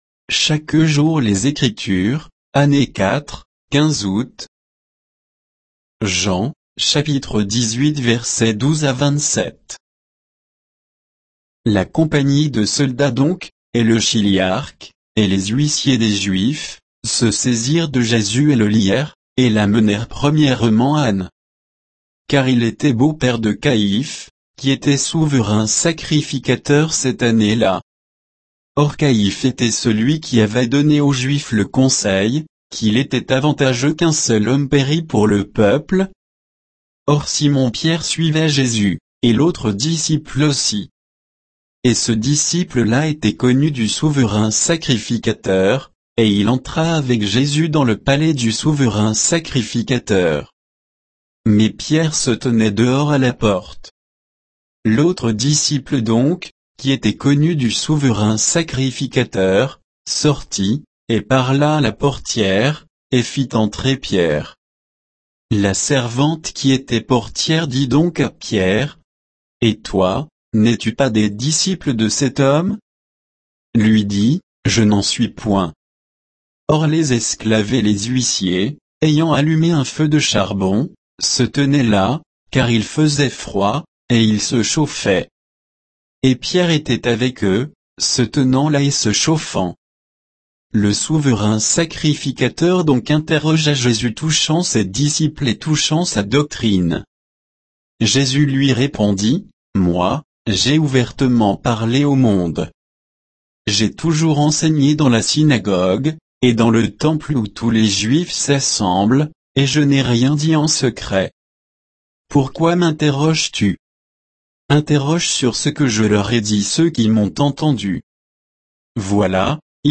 Méditation quoditienne de Chaque jour les Écritures sur Jean 18